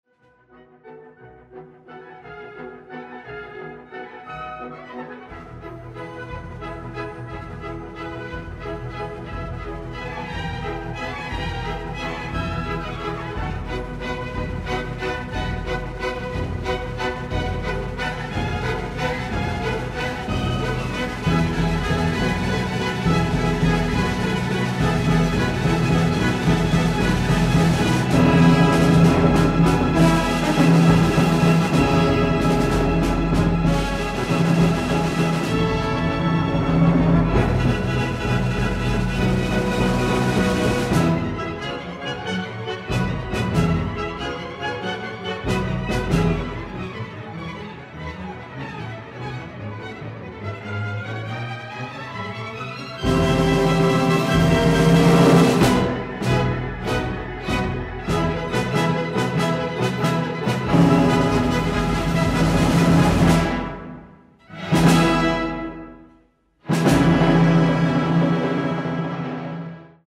La OSY incluyó en su temporada la obertura de la ópera La urraca ladrona del italiano Gioachino Rossini, obra que refleja el carácter cómico de su repertorio escénico y el uso de redobles, ritmos y crescendos rossinianos memorables y que fue estrenada en el legendario teatro Scala de Milán el 31 de mayo de 1817.